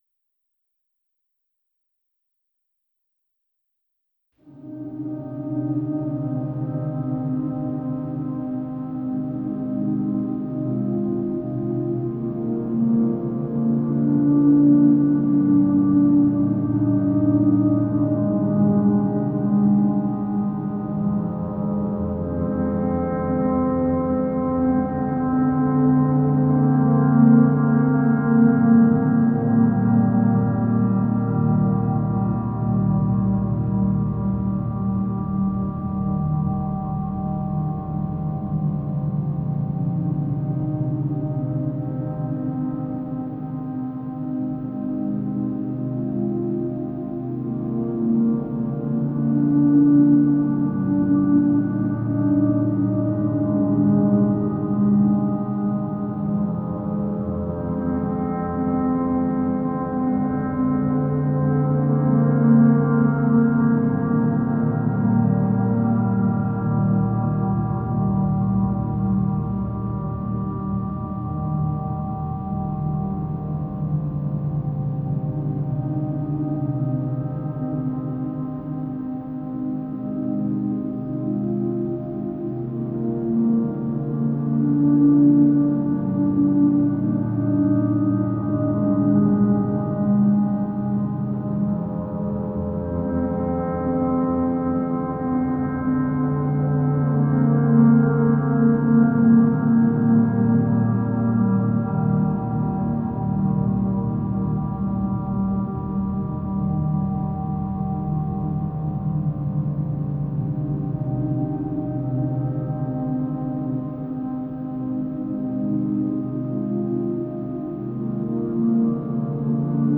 Genre: Ambient, New Age, Meditative.